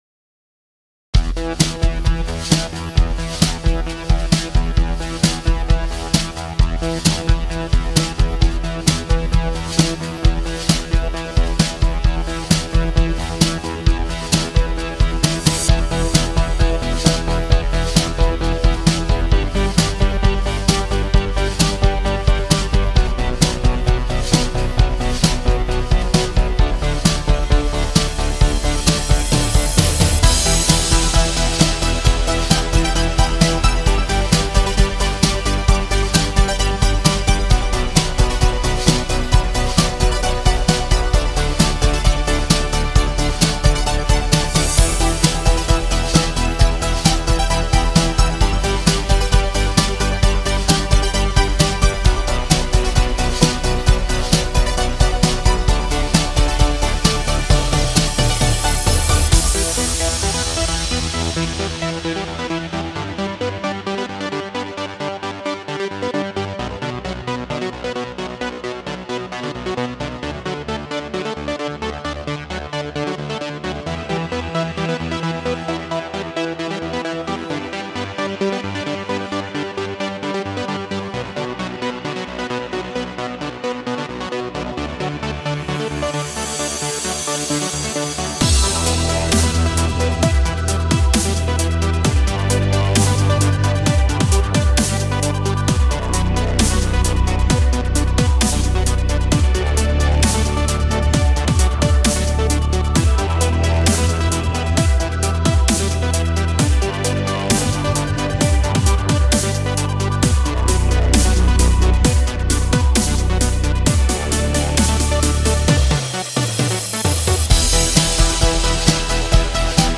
An experimental track... why?